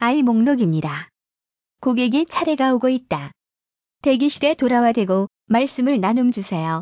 スピーカを設置すれば、受付した言語で音声アナウンスを流せますのでご案内業務も安心です。
韓国語版（例）ご案内の際、店舗不在時の音声